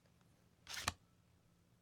card_played.mp3